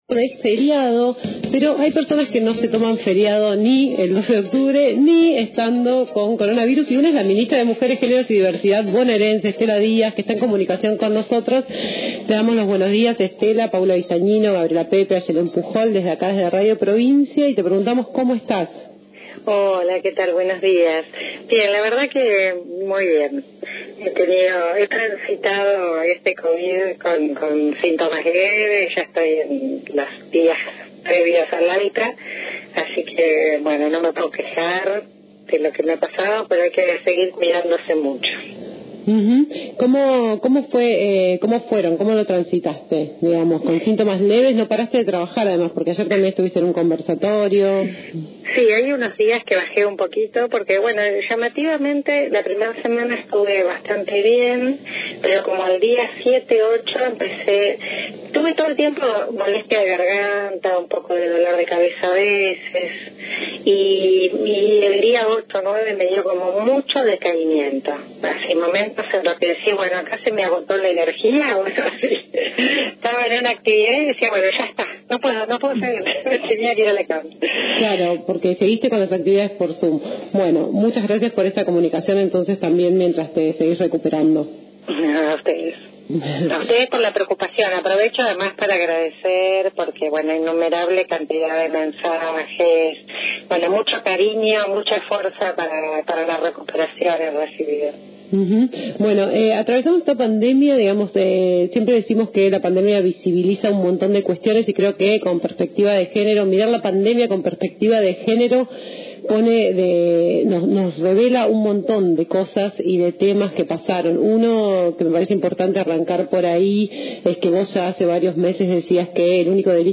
En declaraciones al programa Todo este ruido por radio Provincia, la funcionaria también se refirió a la intervención del gobierno de Axel Kicillof en la toma de Guernica, analizó los números que arroja la pandemia en materia de violencia de género y fue optimista al referirse a la despenalización del aborto.